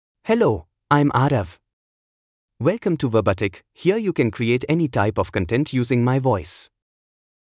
MaleEnglish (India)
Aarav is a male AI voice for English (India).
Voice sample
Listen to Aarav's male English voice.
Aarav delivers clear pronunciation with authentic India English intonation, making your content sound professionally produced.